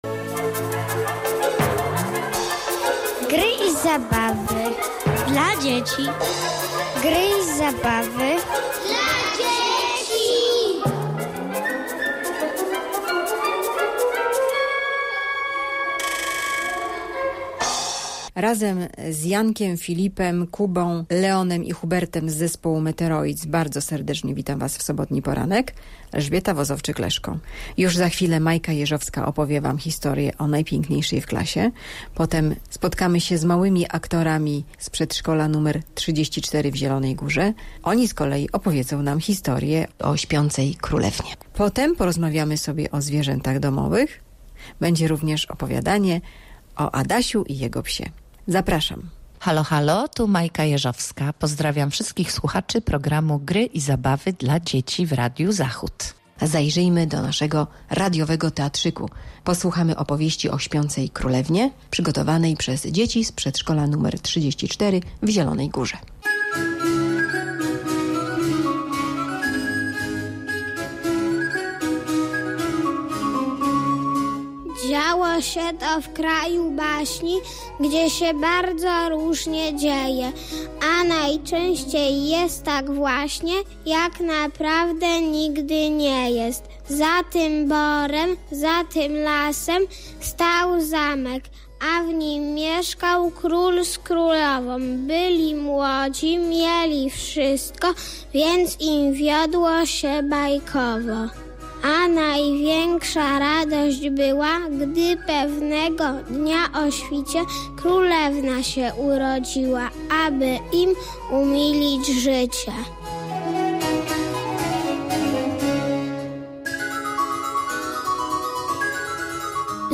Słuchowisko Śpiąca Królewna. Dlaczego dzieci chcą mieć zwierzęta i co to są zwierzęta domowe.